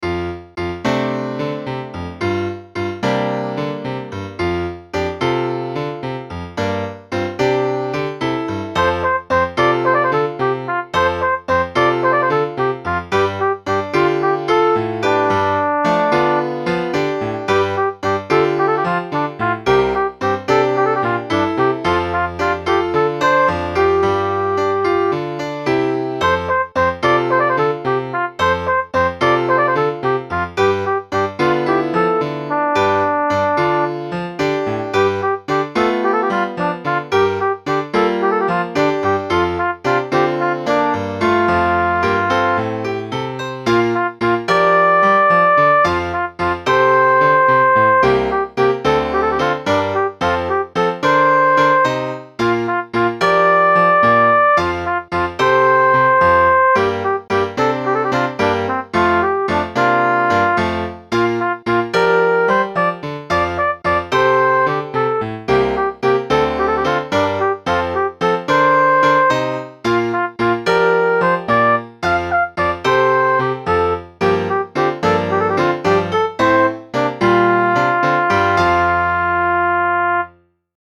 Concert piece